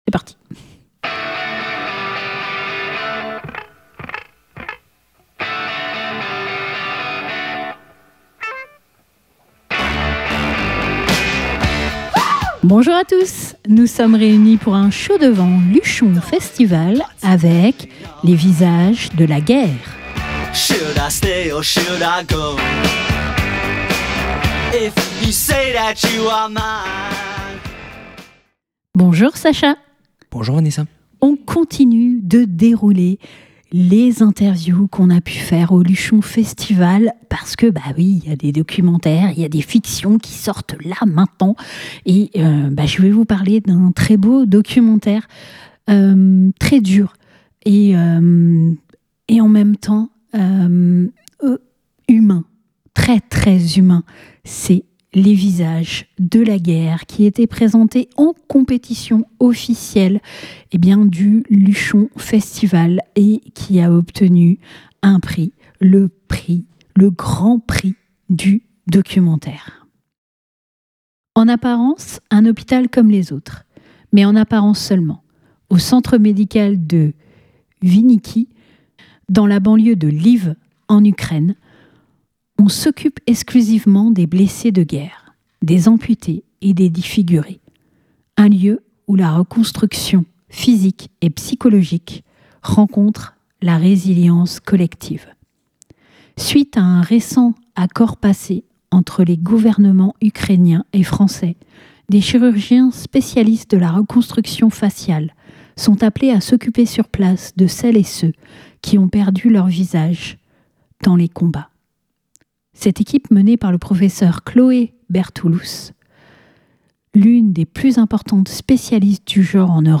18 février 2026 Écouter le podcast Télécharger le podcast Le documentaire "Les visages de la guerre" a reçu le Grand Prix du Documentaire au Luchon Festival.